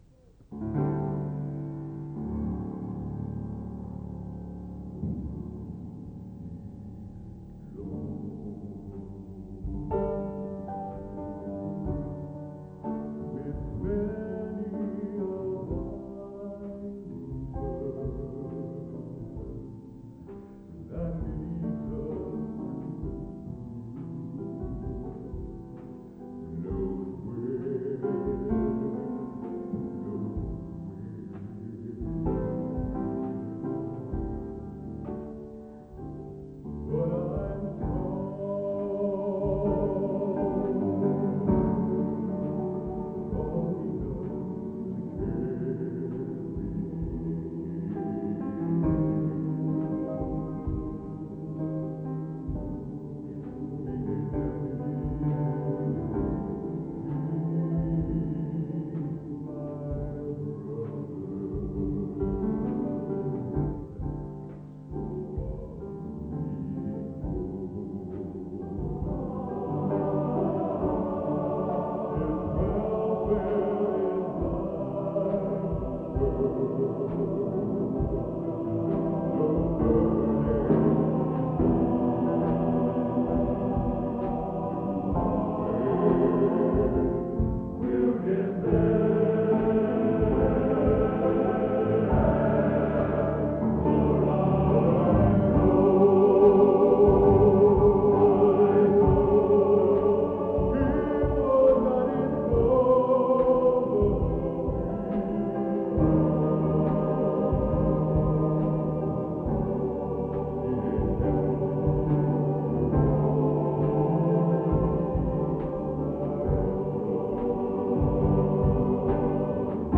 Genre: | Type: Featuring Hall of Famer |Solo